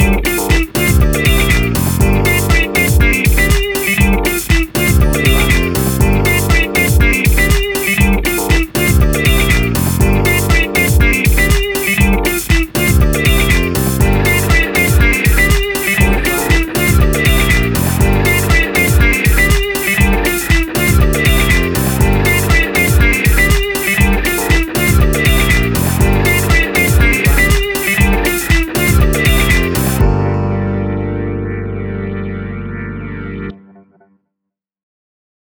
Tempo (BPM): 120